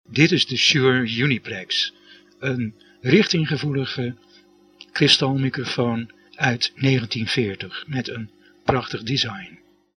SHURE 730A 'UNIPLEX' CARDIOIDE KRISTALMIC
Omdat de capsule sowieso minder gevoelig was voor de hogere frequenties, had het nieuwe kristalelement een goede richtingsgevoeligheid over het volledige audiobereik van 30 tot 10.000 Hz.
Omdat hij niet veel van het omgevingsgeluid oppikte, was het geluid van de Uniplex veel schoner dan dat van andere microfoons in de lage of gemiddelde prijsklasse.
Shure Uniplex sound NL.mp3